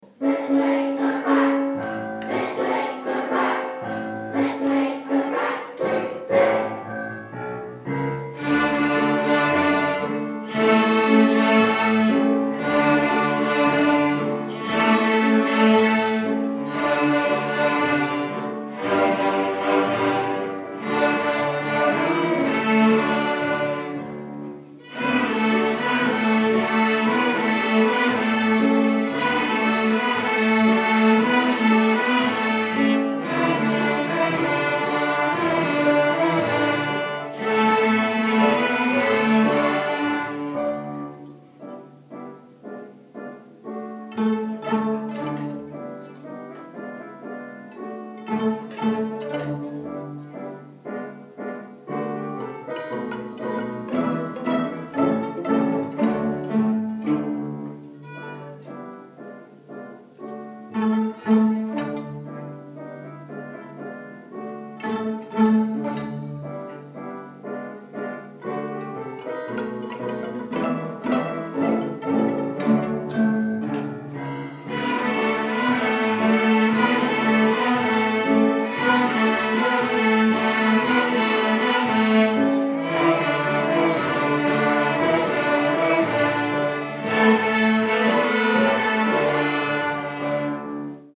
Orchestra Strings - Let's Play a Rag